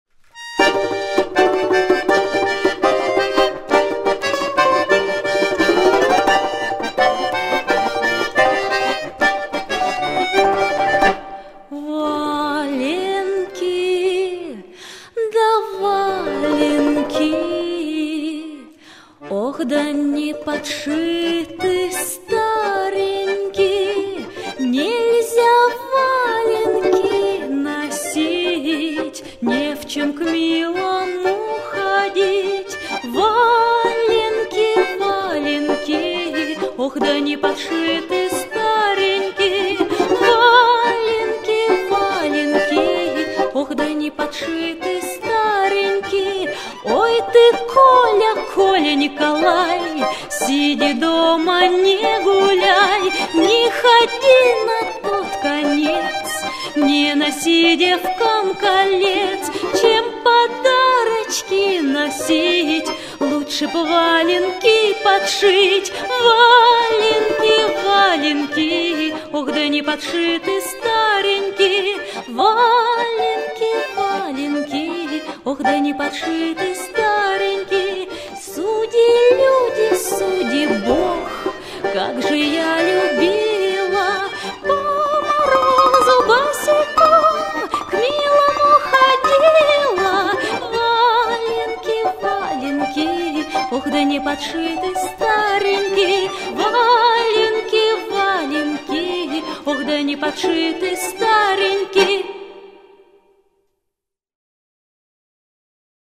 Слова и музыка народные.